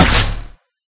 Bow3.ogg